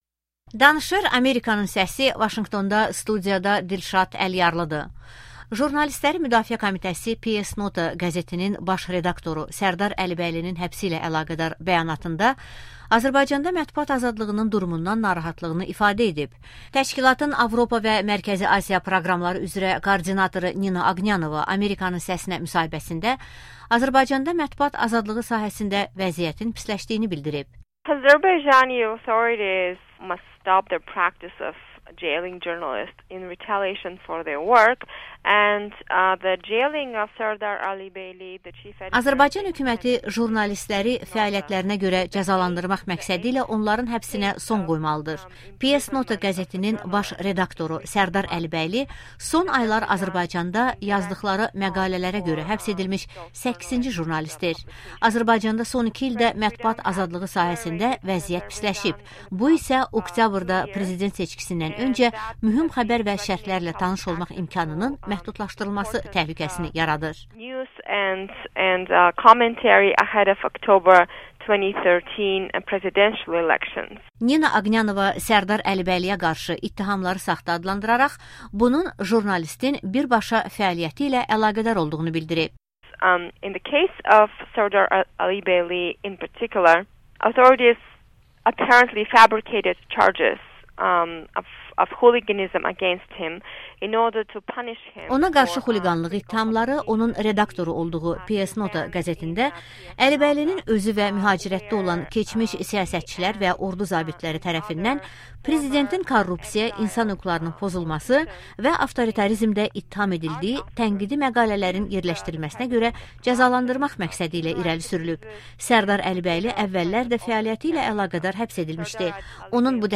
Amerikanın Səsinə müsahibəsi